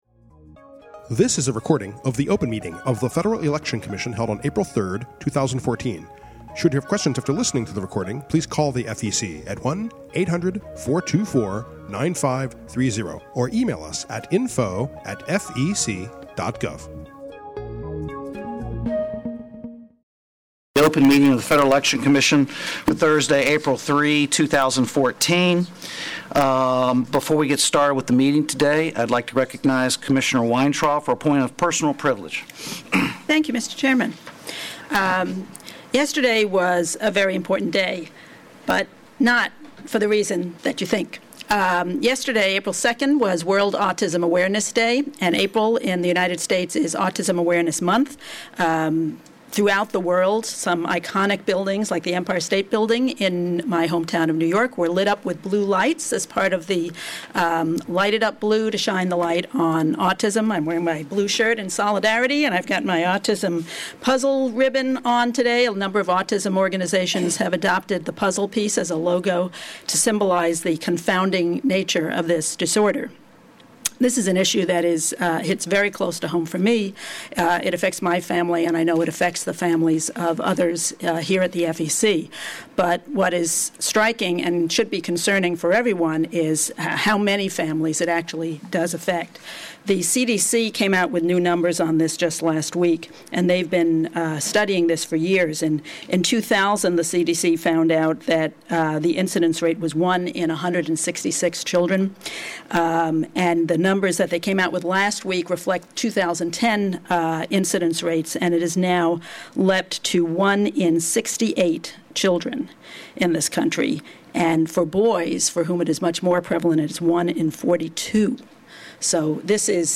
April 3, 2014 open meeting